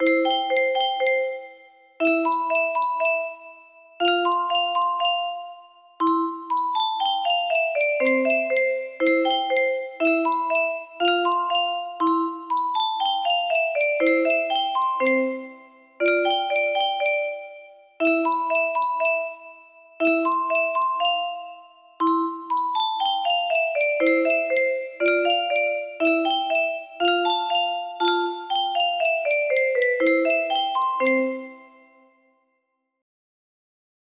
20208 music box melody